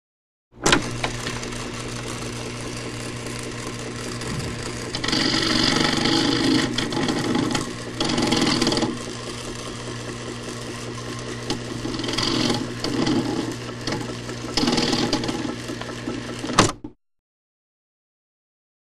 Polygraph Readout; Polygraph Machine; Switch On / Clatter / Grinding / Switch Off, Close Perspective. Chart Recorder.